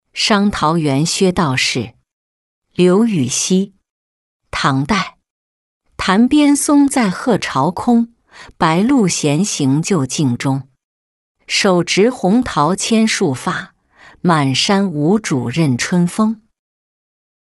伤桃源薛道士-音频朗读